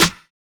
TENSION SNARE.wav